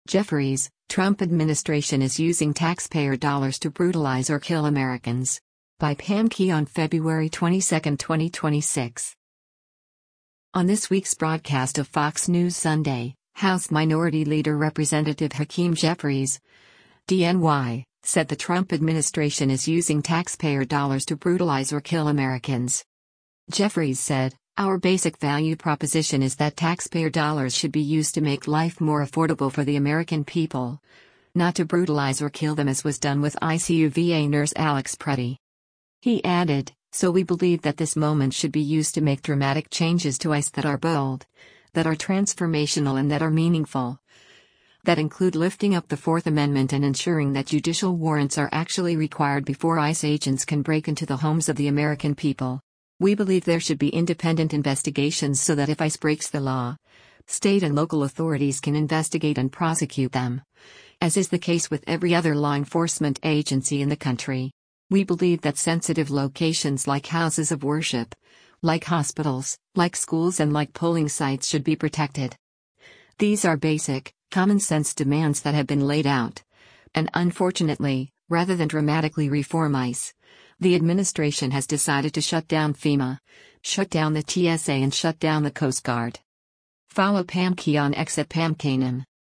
On this week’s broadcast of “Fox News Sunday,” House Minority Leader Rep. Hakeem Jeffries (D-NY) said the Trump administration is using taxpayer dollars to “brutalize or kill” Americans.